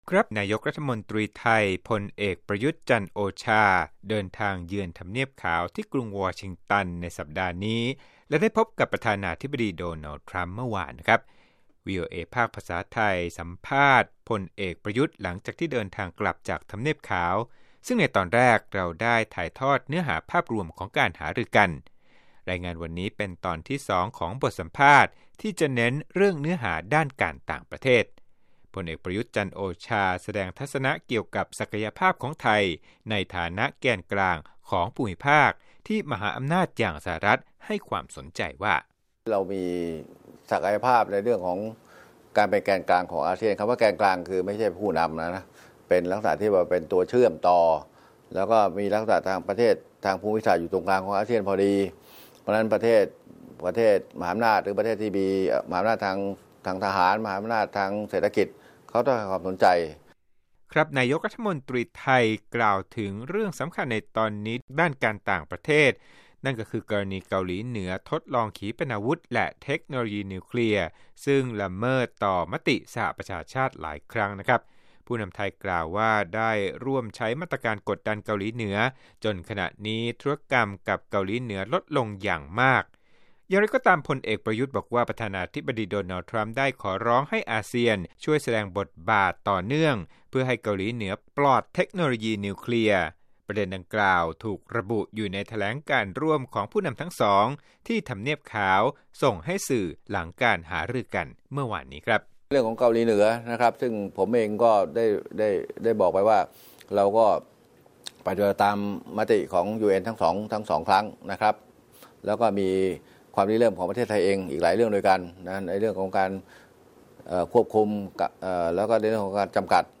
สัมภาษณ์พิเศษ: พล.อ. ประยุทธ์ ยืนยันบทบาทไทยที่สามารถช่วยสหรัฐฯ ได้เรื่องเกาหลีเหนือ